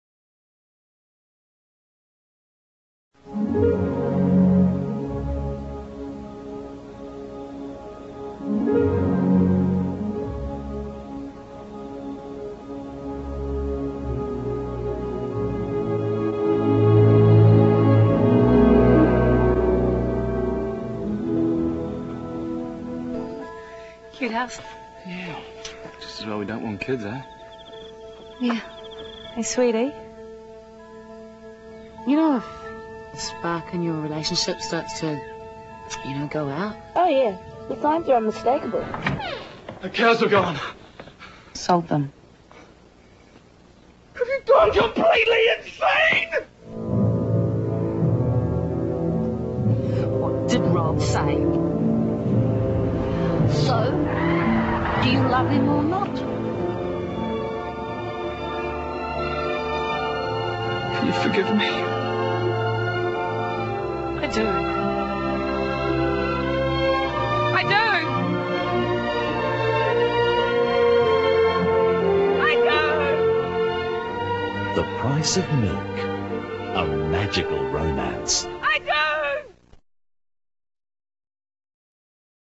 予告編